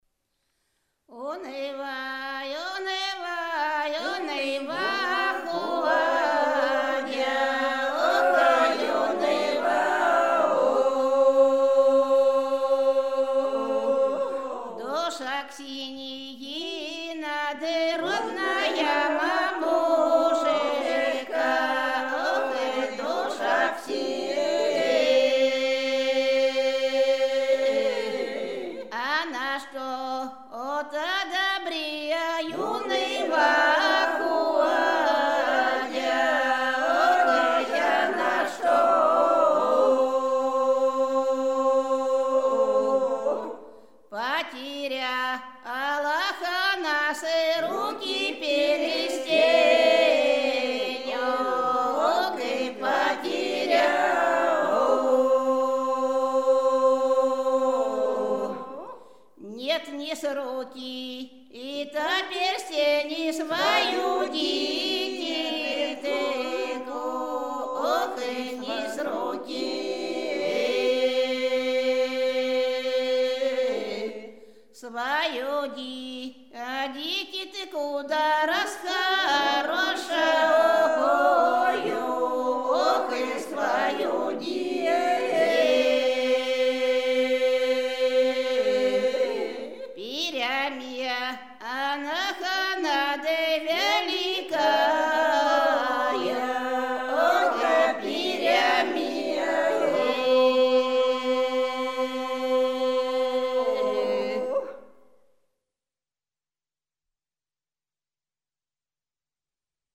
Рязань Секирино «Уныва, уныва ходя», свадебная «горевая».